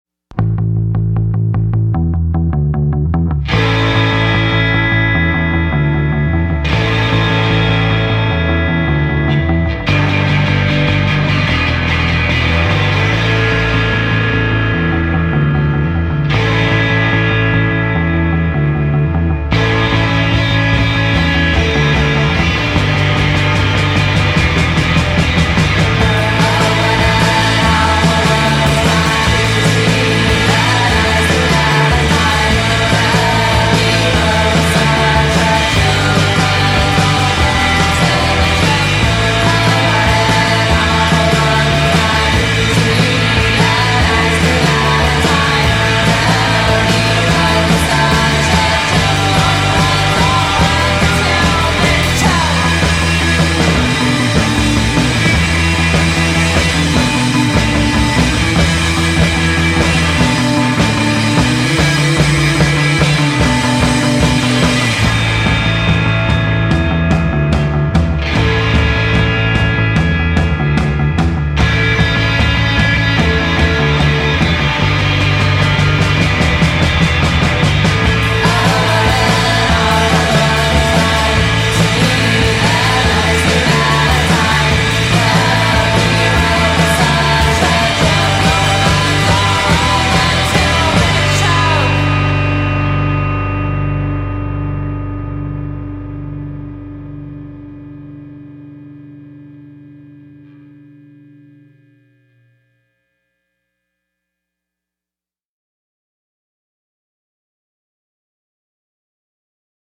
Mi sono imbattuto in queste tre ragazze